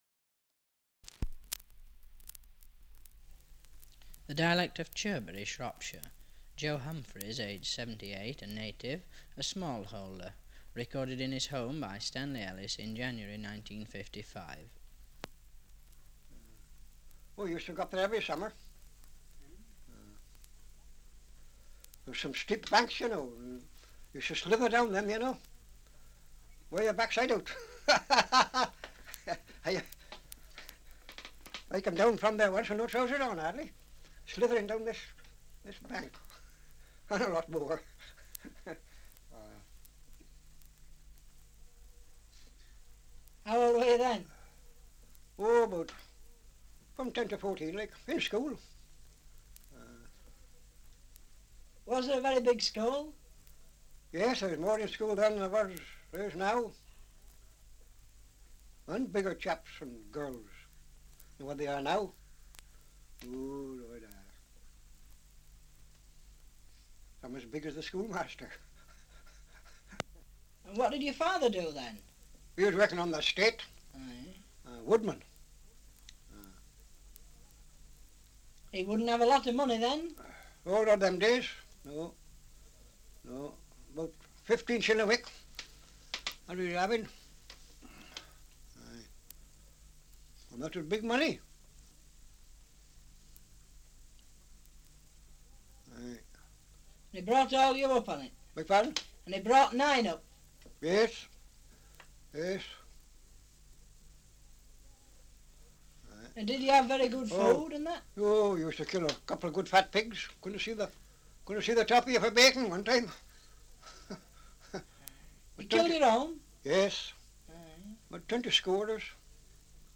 1 - Survey of English Dialects recording in Chirbury, Shropshire
78 r.p.m., cellulose nitrate on aluminium